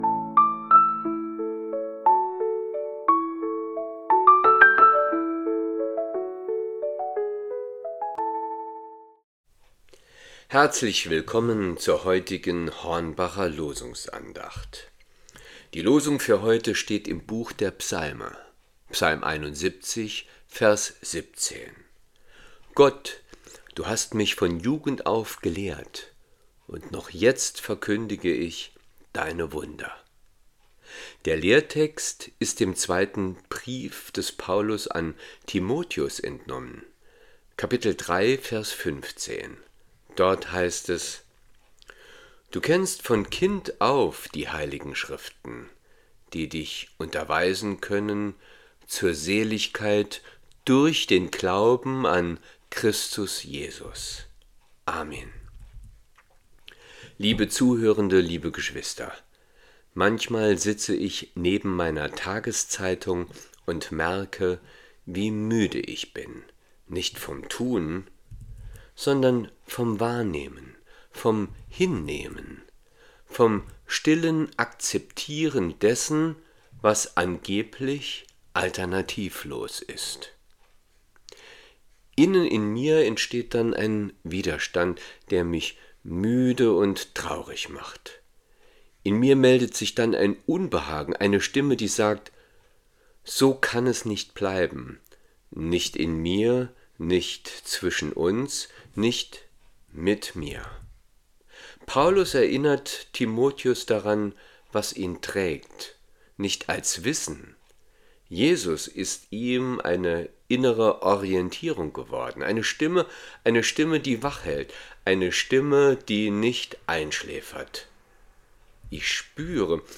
Losungsandacht für Montag, 16.03.2026 – Prot. Kirchengemeinde Hornbachtal mit der prot. Kirchengemeinde Rimschweiler